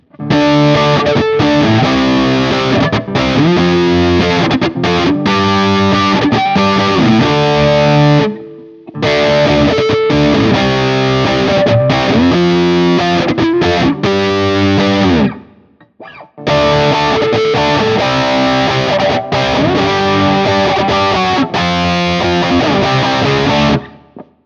Barre
As usual, for these recordings I used my normal Axe-FX II XL+ setup through the QSC K12 speaker recorded direct into my Macbook Pro using Audacity. I recorded using the ODS100 Clean patch, as well as the JCM-800 and one through a setting called Citrus which is a high-gain Orange amp simulation.
For each recording I cycle through the neck pickup, both pickups, and finally the bridge pickup. All knobs on the guitar are on 10 at all times.
Guild-S285-Maybe-Citrus-Barre.wav